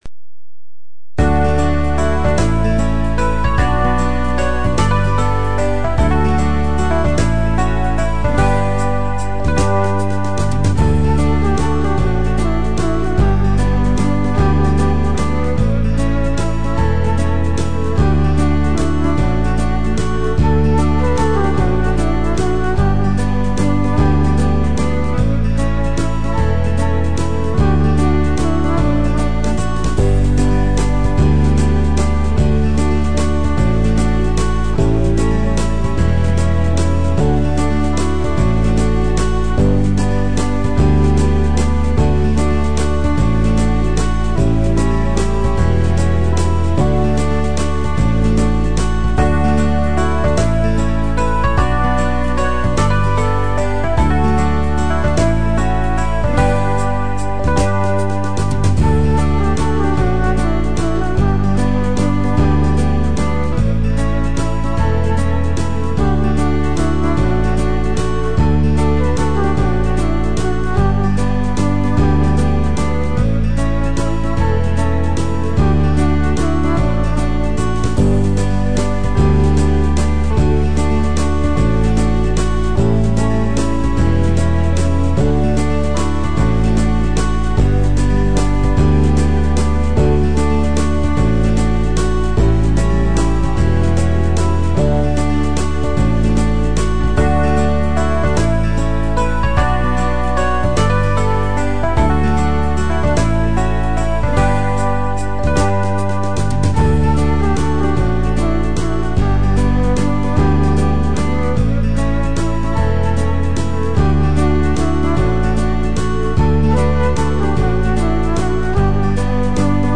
La bicicleta de Nadal – base instrumental
La-bicicleta-de-Nadal-base-instrumental.mp3